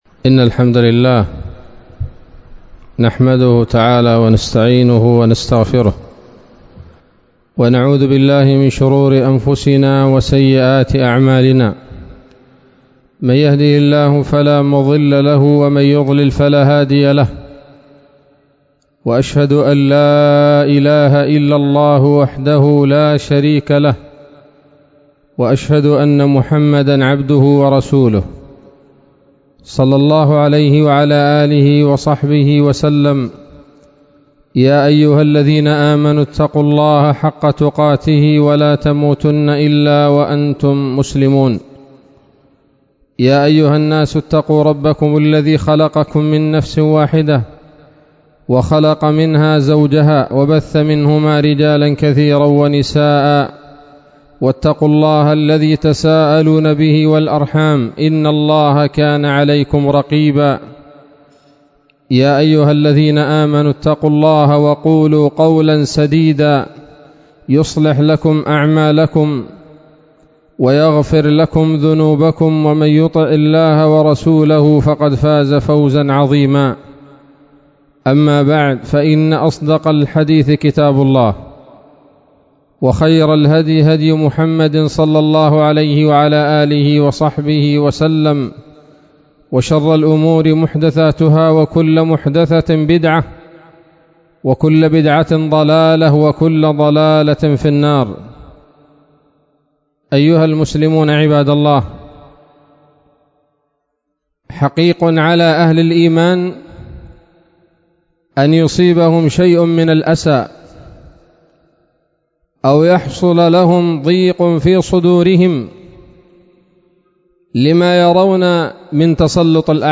خطبة جمعة بعنوان: (( النفير النفير قبل حلول التدمير )) 10 صفر 1443 هـ